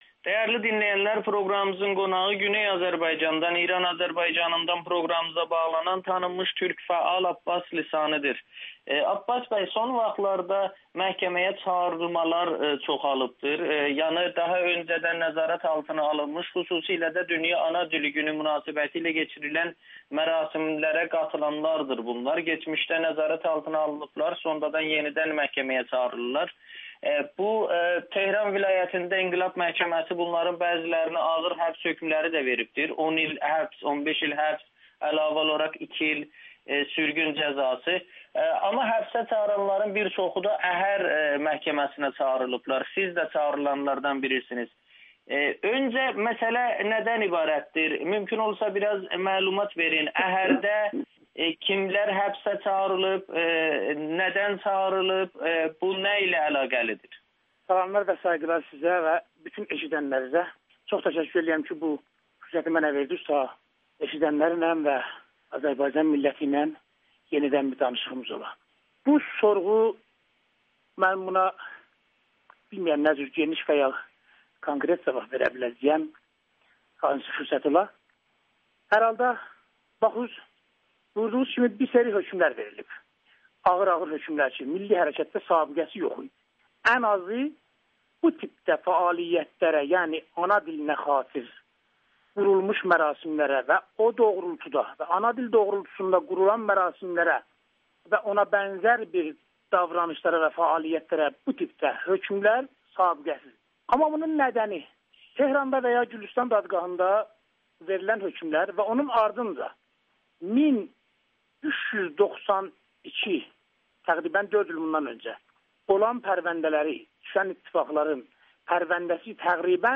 Hakimiyyətin ən böyük arzusu milli hərəkatı radikallaşdırmasıdır [Audio-Müsahibə]